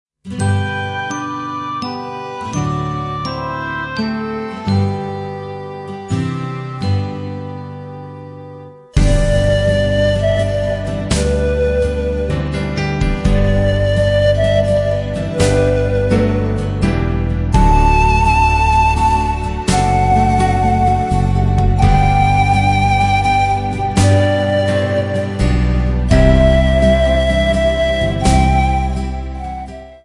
A compilation of Christmas Carols from Poland
panpipes